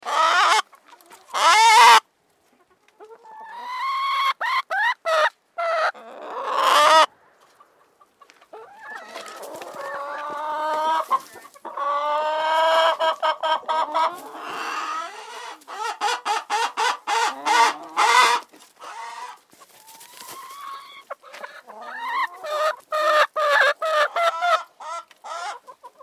На этой странице собраны разнообразные звуки курятника: от кудахтанья кур до петушиных криков на рассвете.
Шум утреннего курятника у бабушки